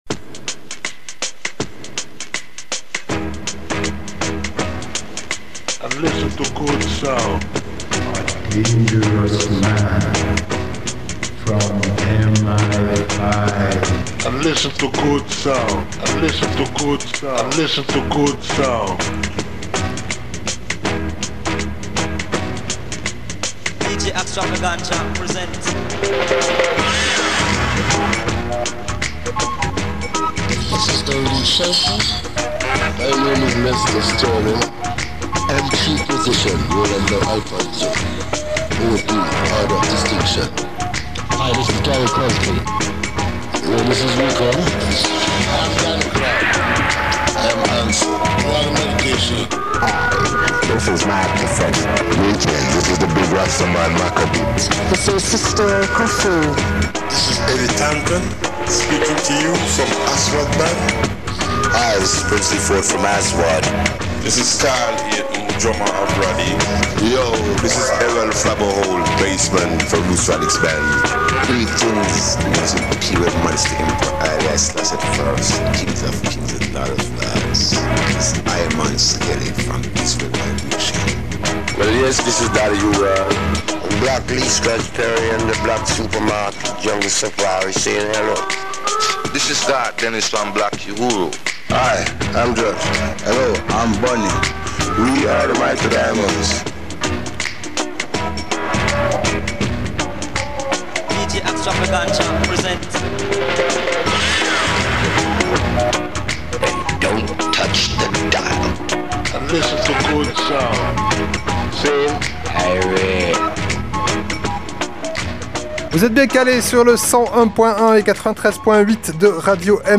radio show !